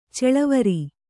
♪ ceḷavari